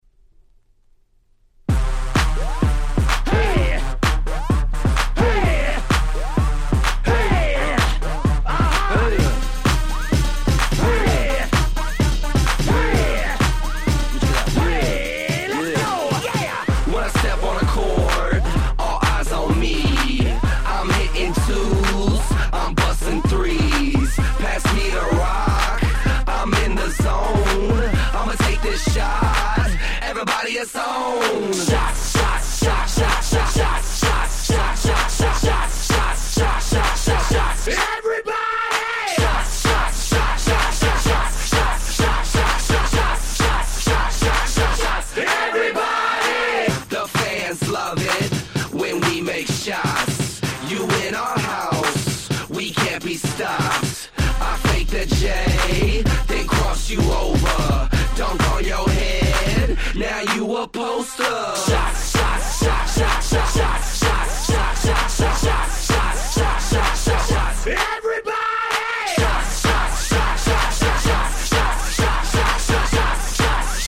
White Press Only EDM/Hip Hop Remixes !!